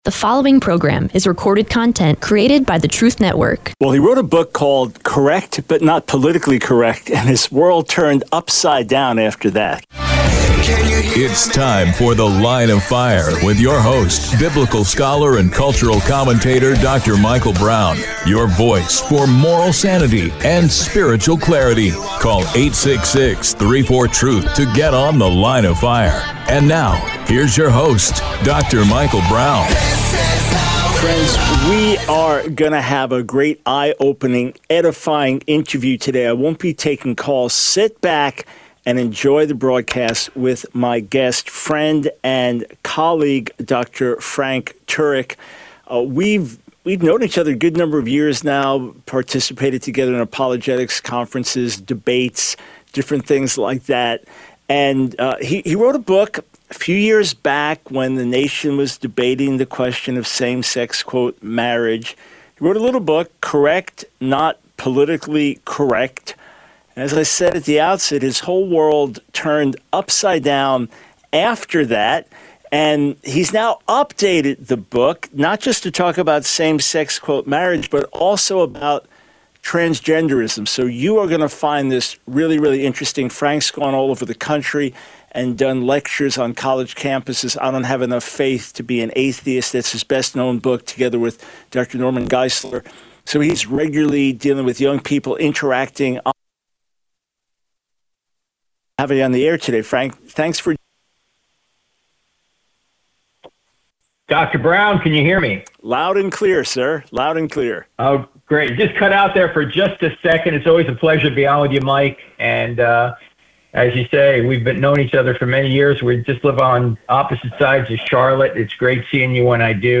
The Line of Fire Radio Broadcast for 01/29/24.